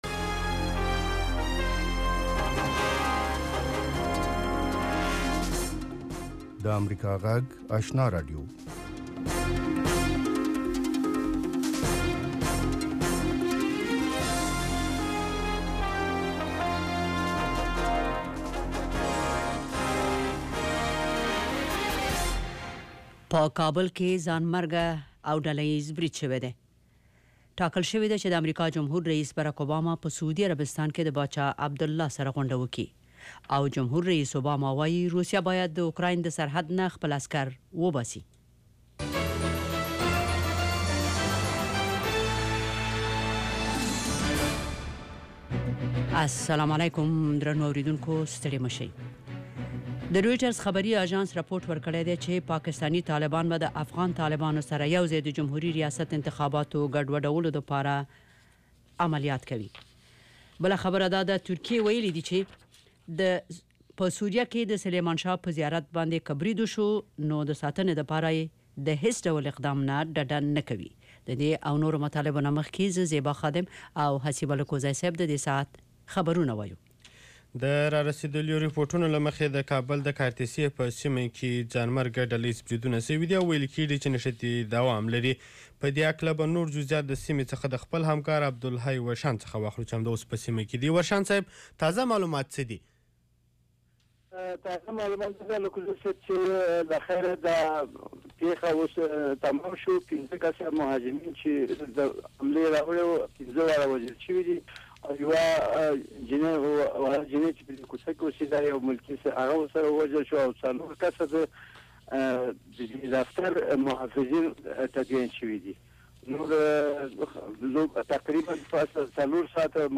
یو ساعته خپرونه: تازه خبرونه، د ځوانانو، میرمنو، روغتیا، ستاسو غږ، ساینس او ټیکنالوژي، سندرو او ادب په هکله اونیز پروگرامونه.